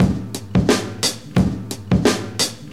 • 88 Bpm Drum Groove G Key.wav
Free breakbeat - kick tuned to the G note. Loudest frequency: 1728Hz
88-bpm-drum-groove-g-key-9h0.wav